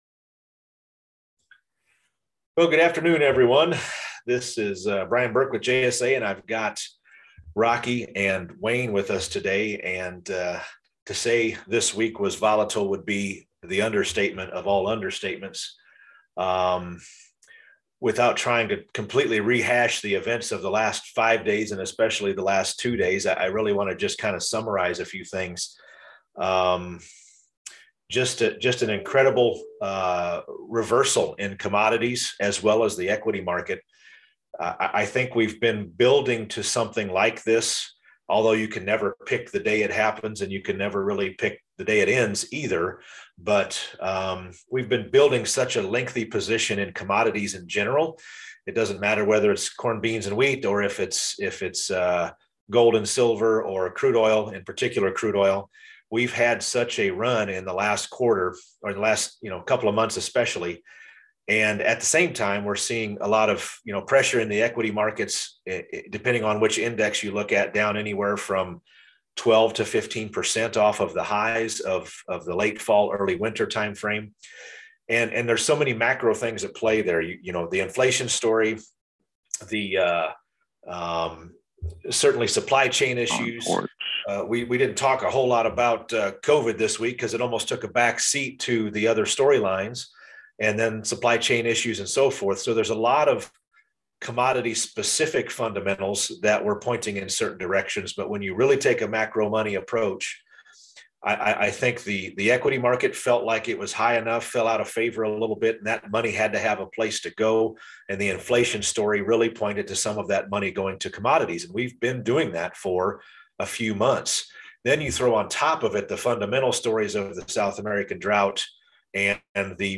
JSA Weekly Conference Call 2/25/2022